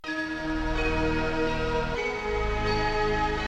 Music: tracker 8-channel .s3m
Creative SoundBlaster 1.5 ct1320